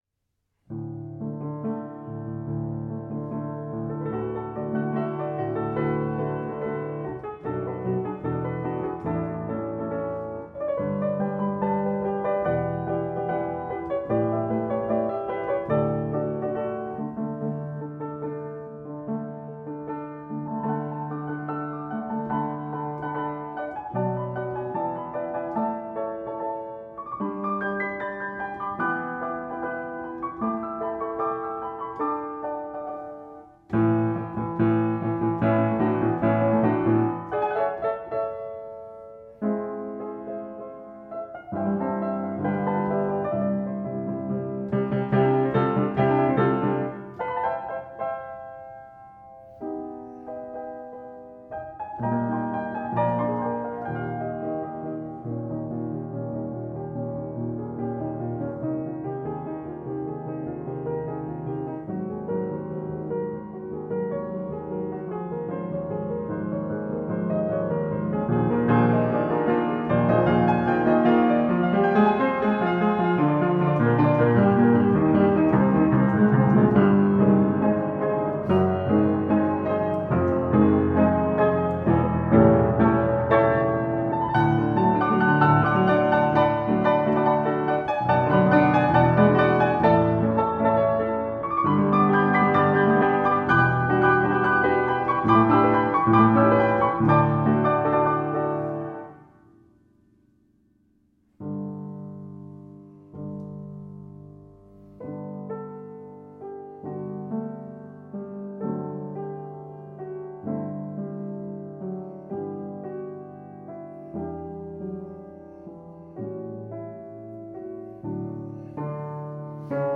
Rönisch Upright Piano, 1915, Unequal Temperament